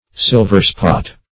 Silverspot \Sil"ver*spot`\, n. (Zool.)